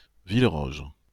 Fichier audio de prononciation du projet Lingua Libre